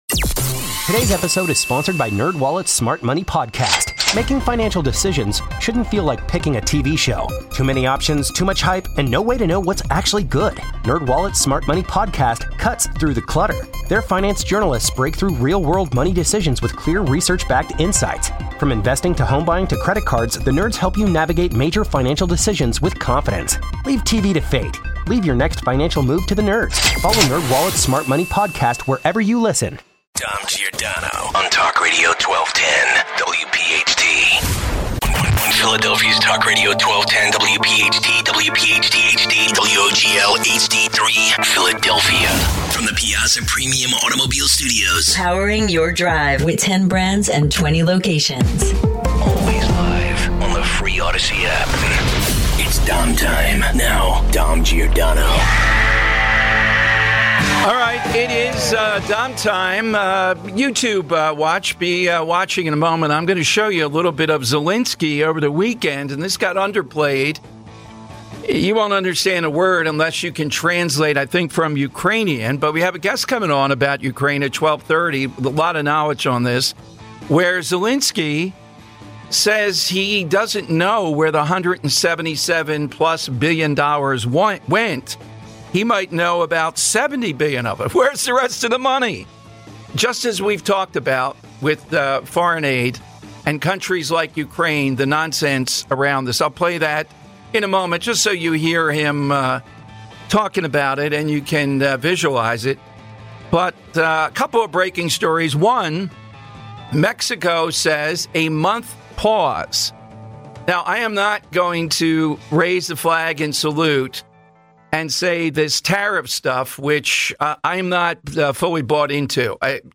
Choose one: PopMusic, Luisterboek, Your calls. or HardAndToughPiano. Your calls.